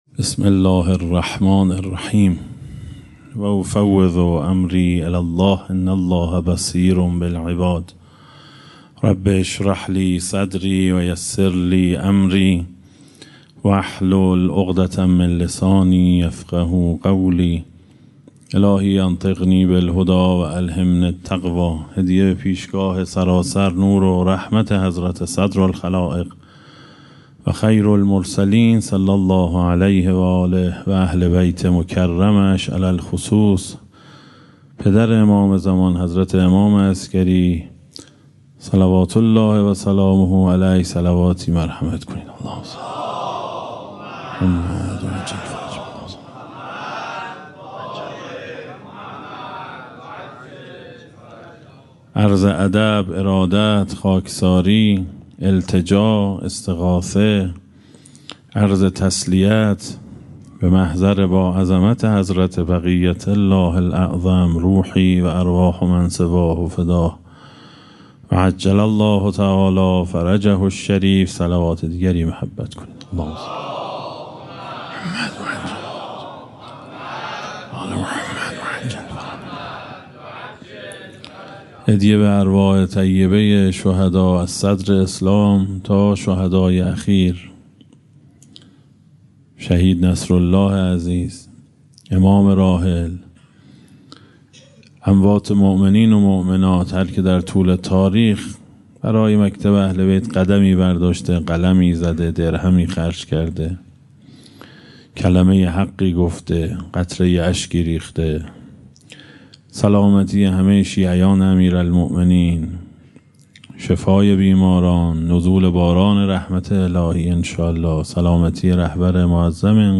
در مسجد گیاهی تجریش به سخنرانی